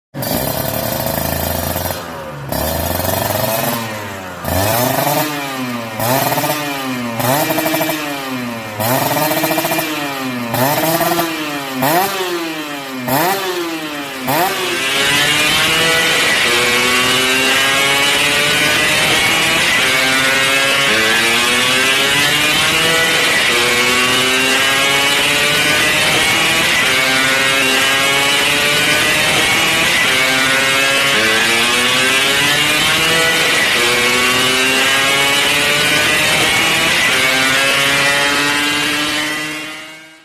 Nada dering suara Motor 2 Tak
Keterangan: Nada dering suara motor 2 tak kini tersedia untuk diunduh dalam format MP3.
nada-dering-suara-motor-2-tak-id-www_tiengdong_com.mp3